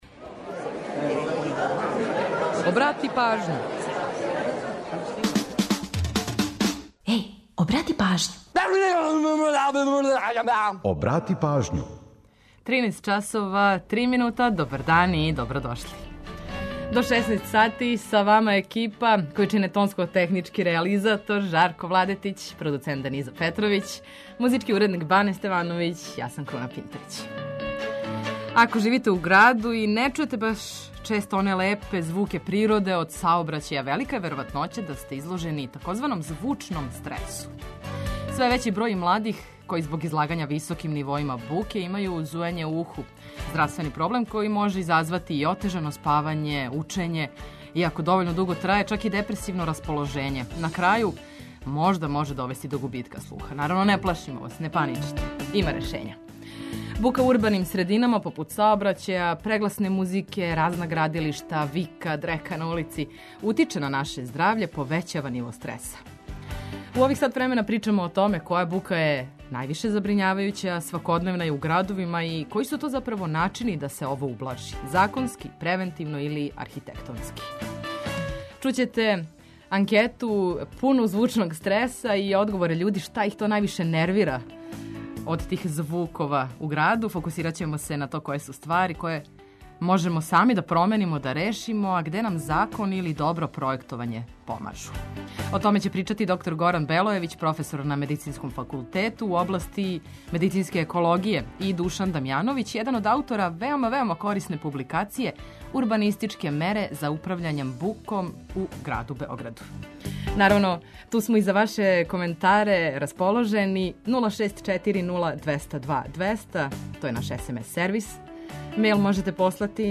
Причамо о томе која бука је највише забрињавајућа, а свакодневна је у градовима и који су начини да се то ублажи - законски, превентивно, архитектонски. Кроз анкету, односно репортажу пуну већ споменутог „звучног стреса" и одговора људи шта их највише нервира, фокусираћемо се на то које су ствари на које можемо сами да утичемо и решимо их, а где нам закон или добро пројектовање помажу.